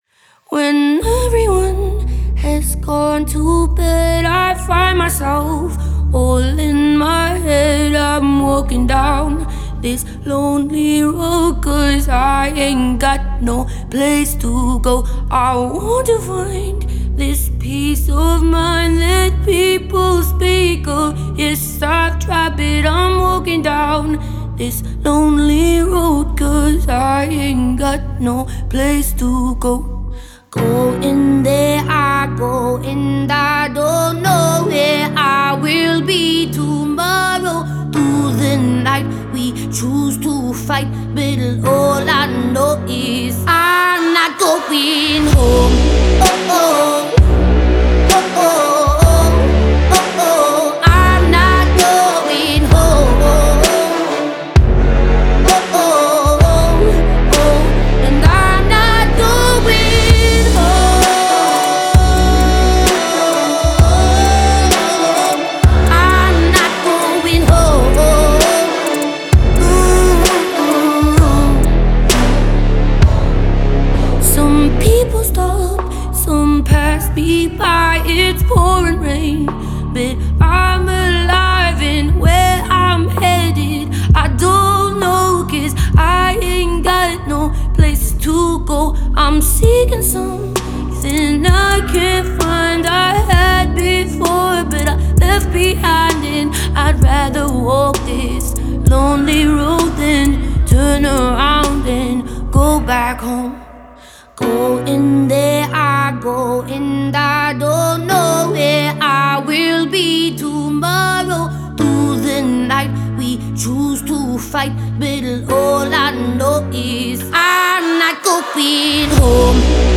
энергичная песня австралийской певицы